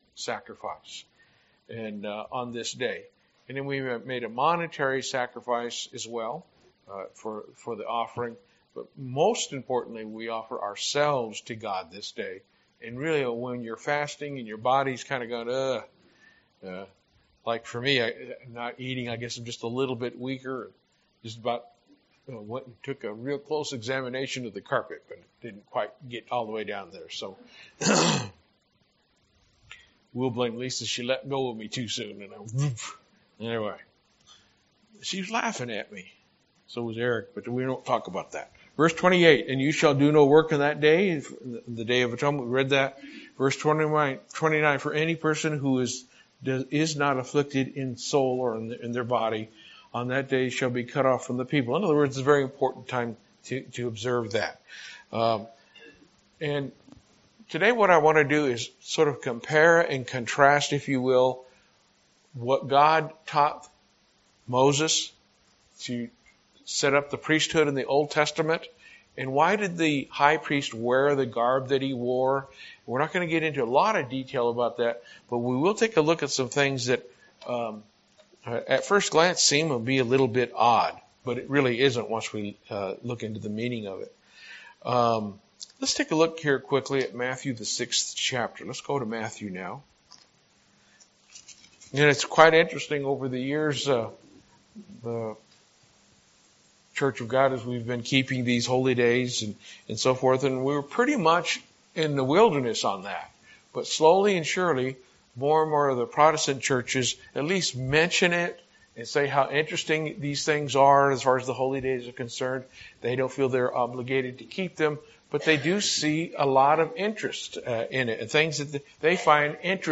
Holy Day Services Studying the bible?